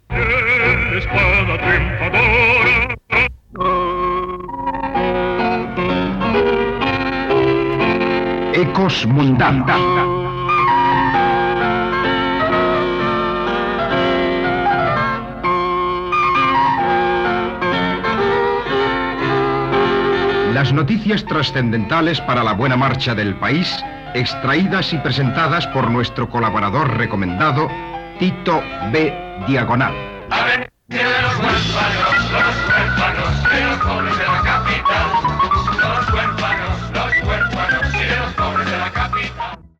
Careta d'entrada
FM